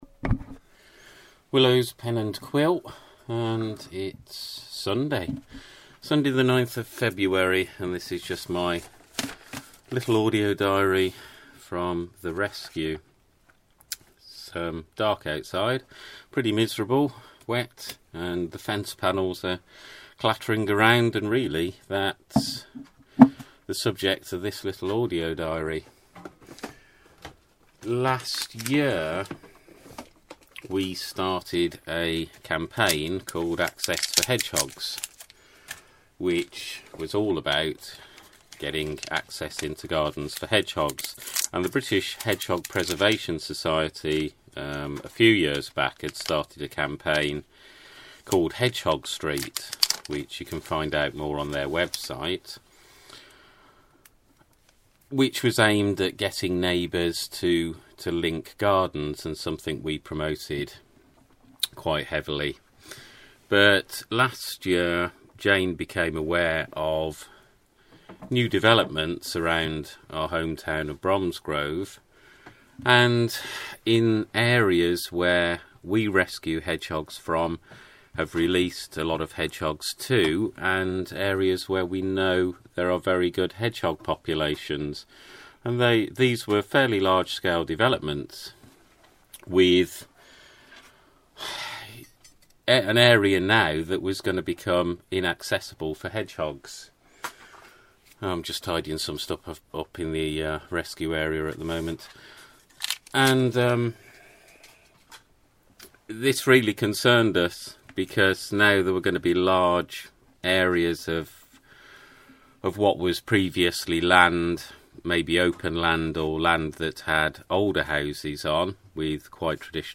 Audio diary of events at Willows Hedgehog Rescue.